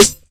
Trs_Snr.wav